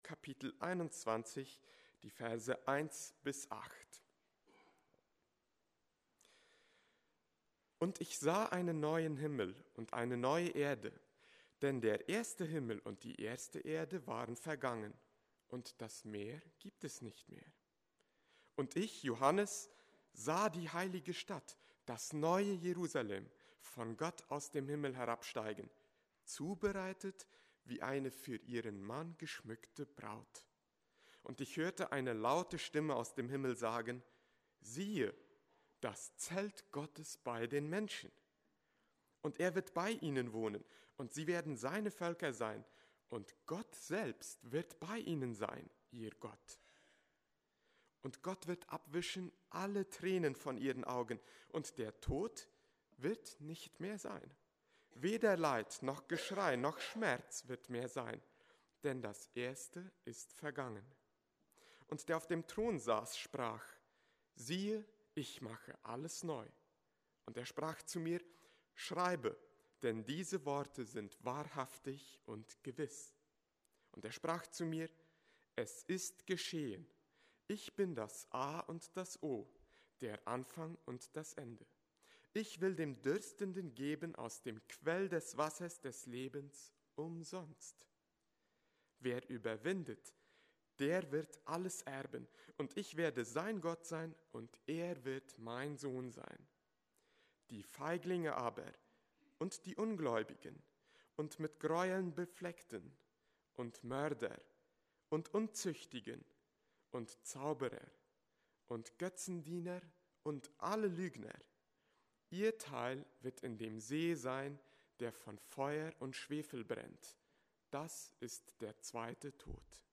Mennonitengemeinde Basel-Holee Webseite 2018
Predigt: Jahreslosung 2018